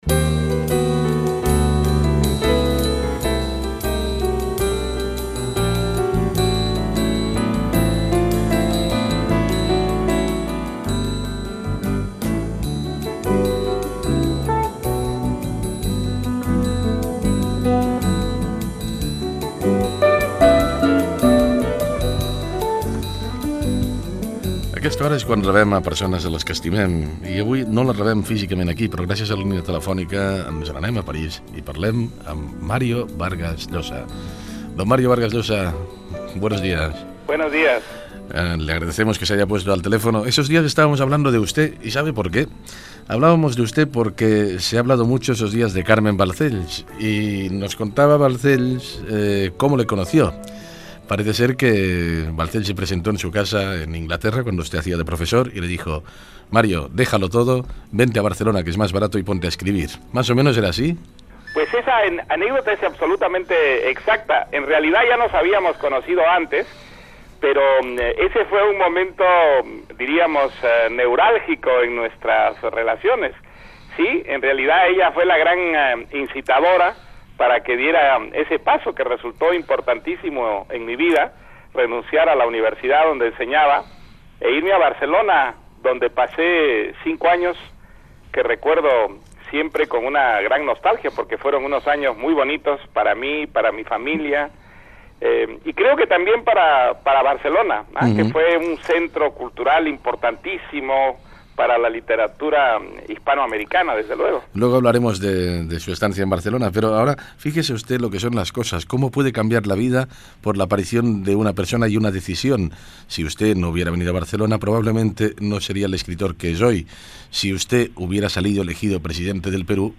Fragment d'una entrevista telefònica a l'escriptor Mario Vargas Llosa que està a París.
Info-entreteniment